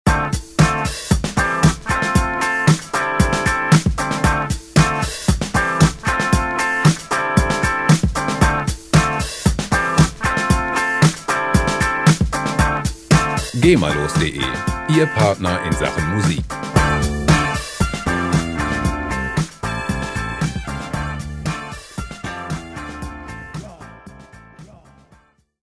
Musikstil: Funk Rock
Tempo: 115 bpm